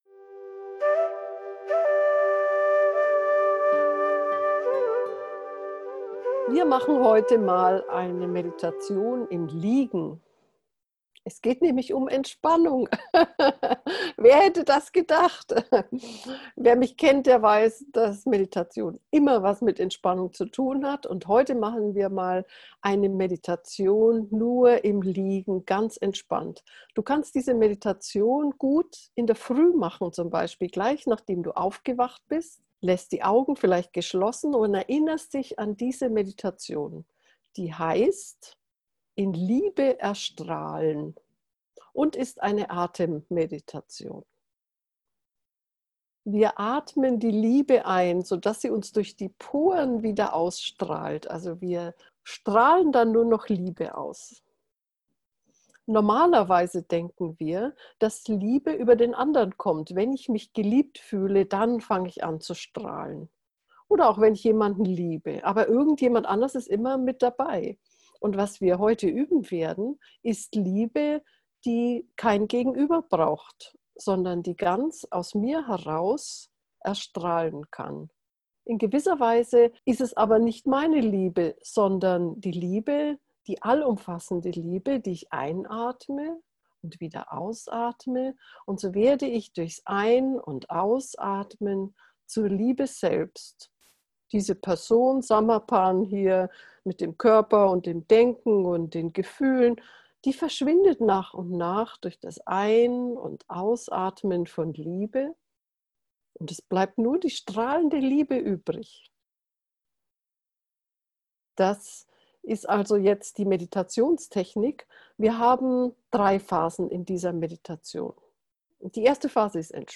In dieser geführten Atemmeditation erstrahlst du in Liebe und nimmst dieses Strahlen mit in den Tag. Die Meditation wird im Liegen gemacht und ist sehr entspannend.